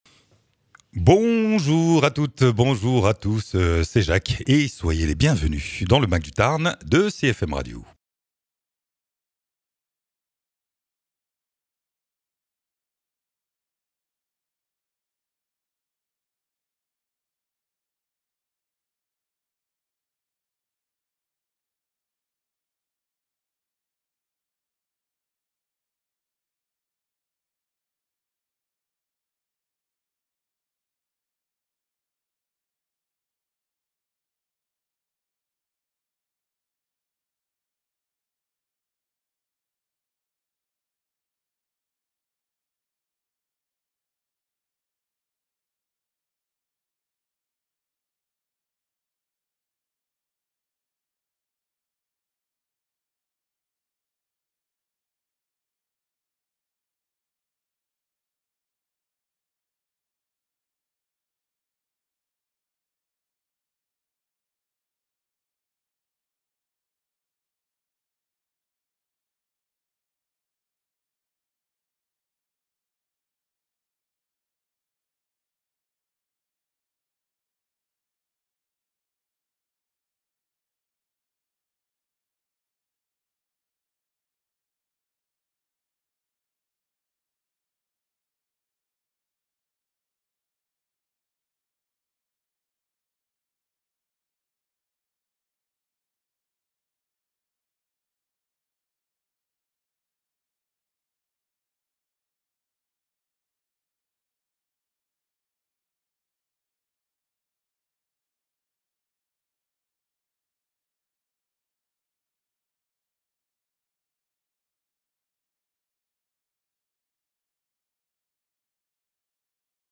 Une balade sonore au cœur du musée de l’Abbaye de Gaillac, un lieu emblématique qui raconte l’histoire du territoire à travers ses collections archéologiques, ses traditions populaires et la mémoire de la vigne et du port gaillacois.
Interviews